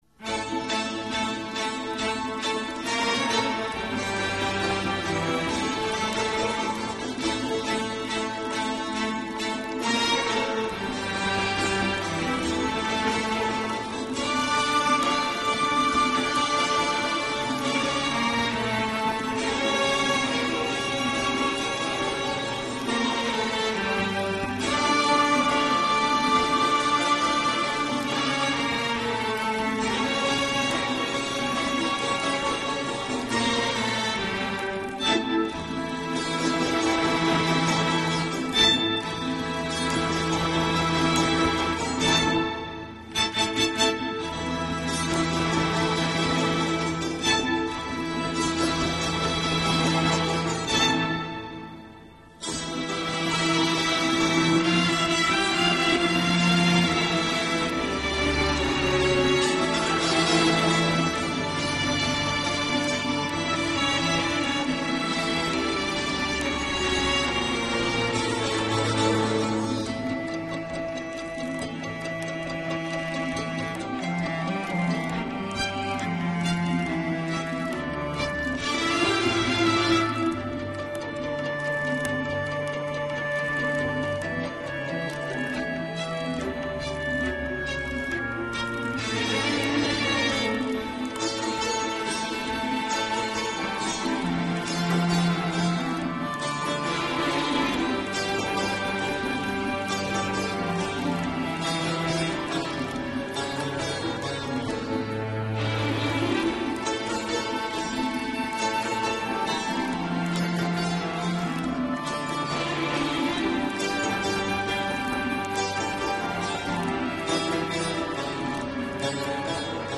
ترانه آرامبخش سنتی